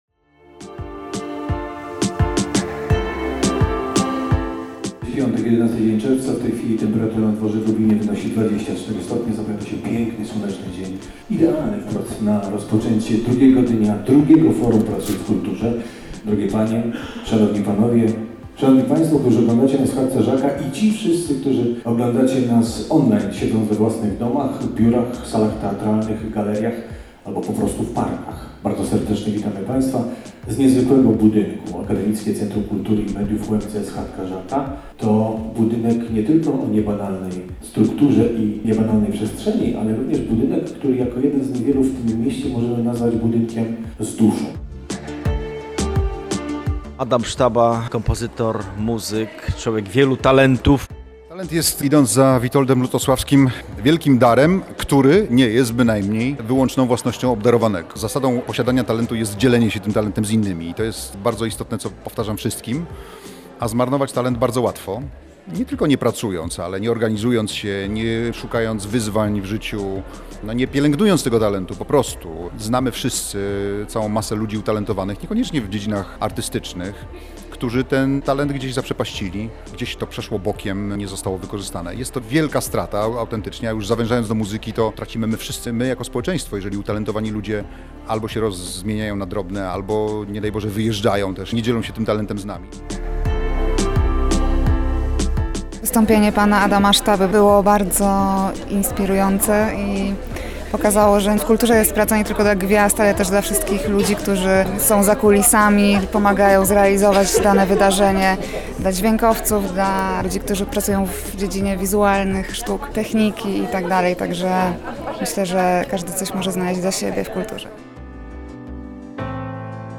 Wzięliśmy udział w drugiej edycji Forum Pracuj w Kulturze.